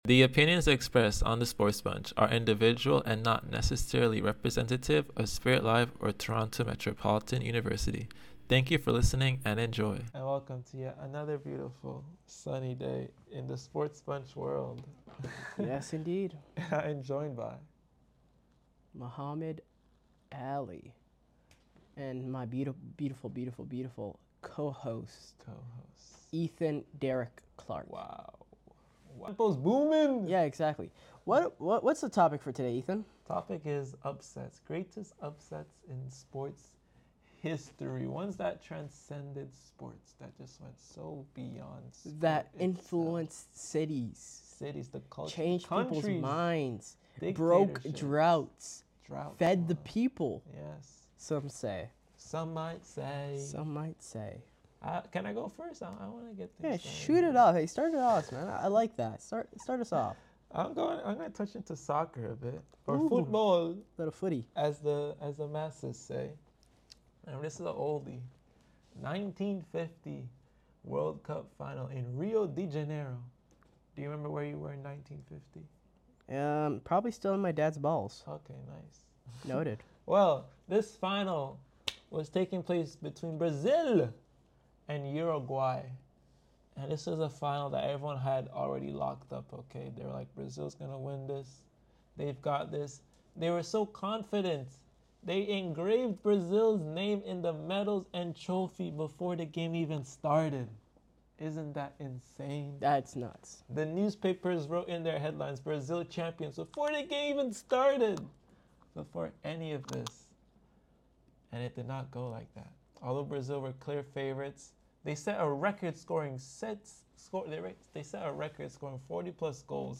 SportsBunch is a student-led talk show where bold opinions, sharp analysis, and real conversations bring the world of sports to life.